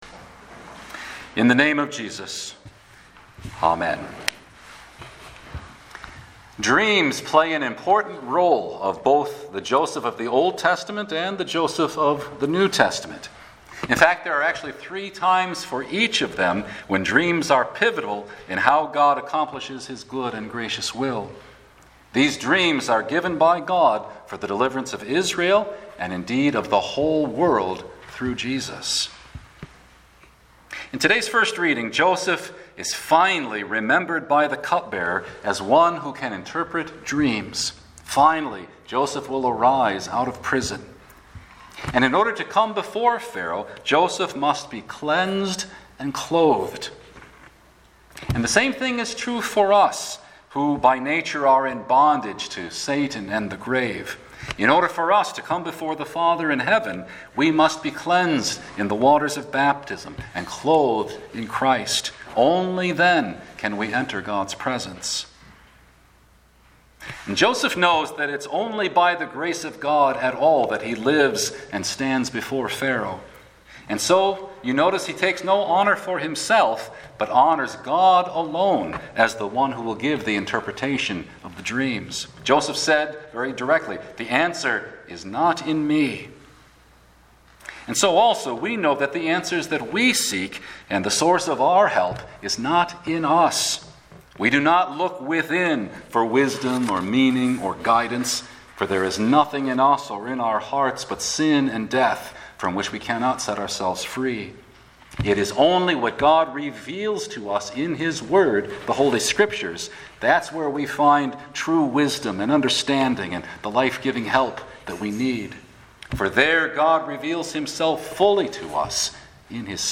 Genesis 41:1-56 Midweek Lent 4